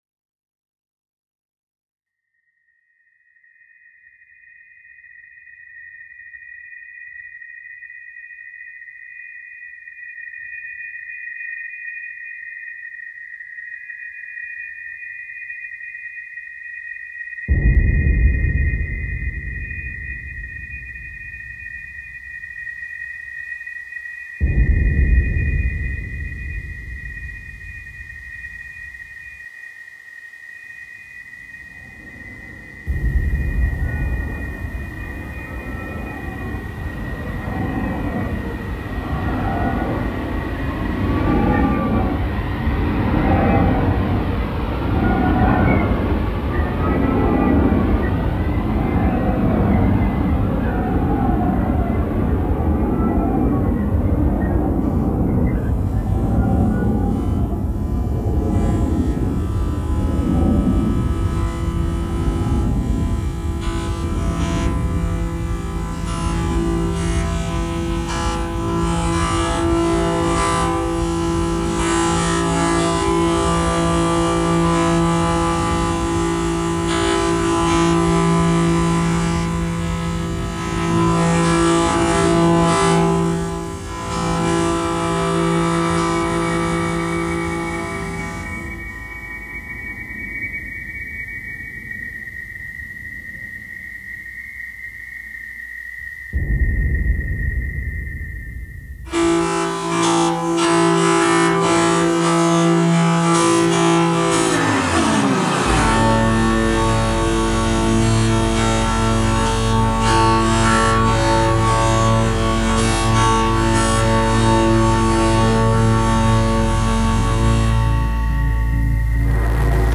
stereo version of the multichannel sound installation
Sound Art